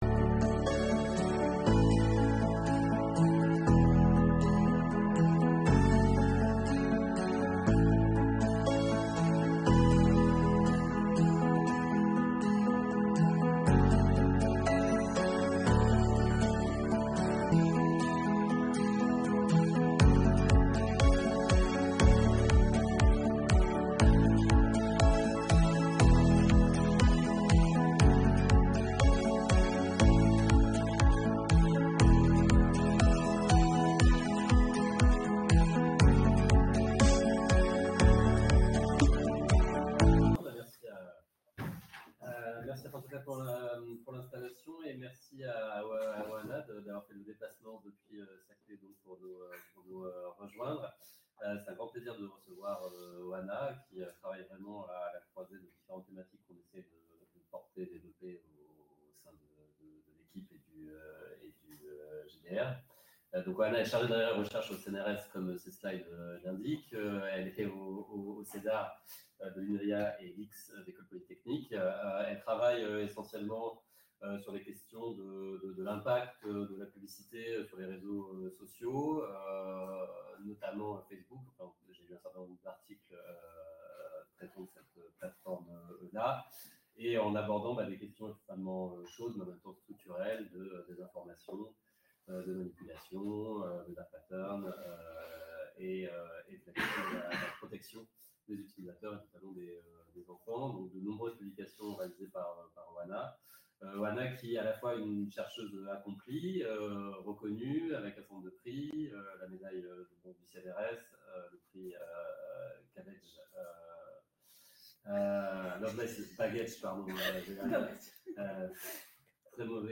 Le séminaire du CIS